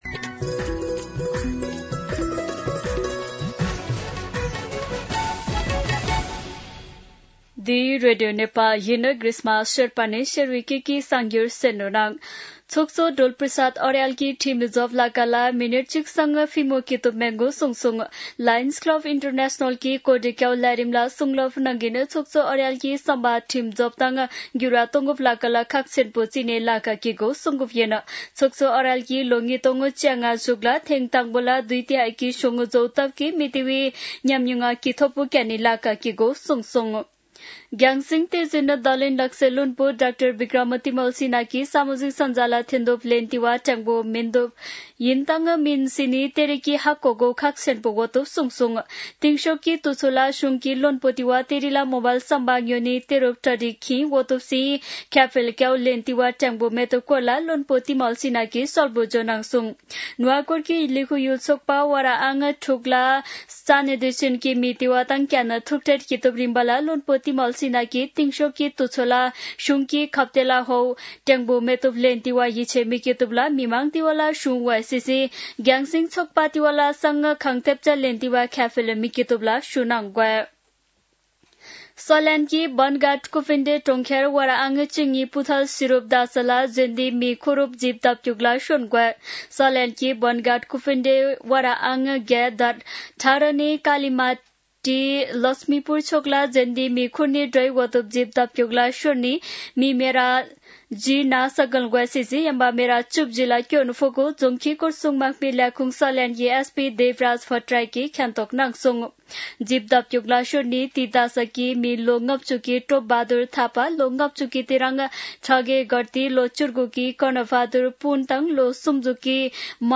शेर्पा भाषाको समाचार : ६ वैशाख , २०८३
Sherpa-News-06.mp3